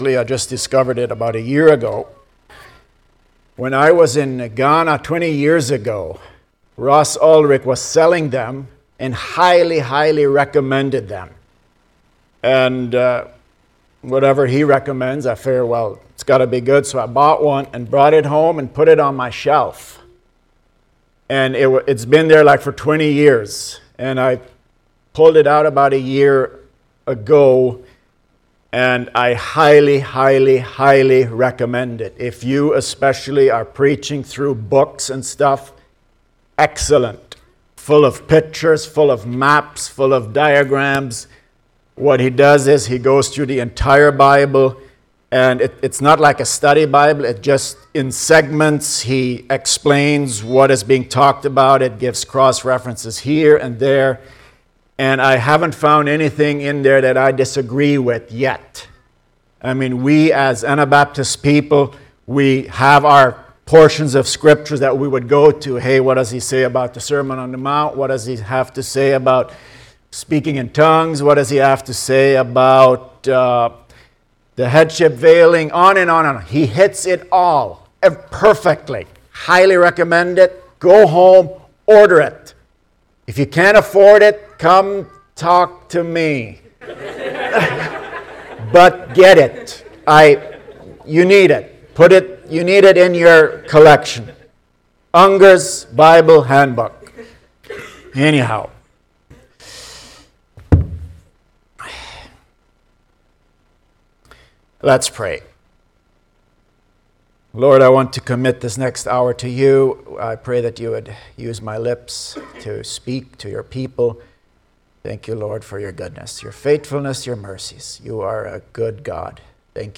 Service Type: Sunday Morning Topics: Eschatology